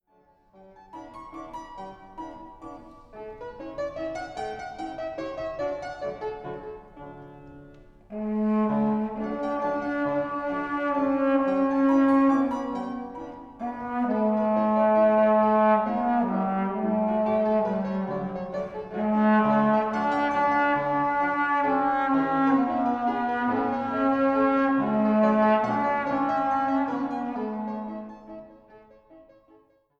Klassische Posaune
Hammerklavier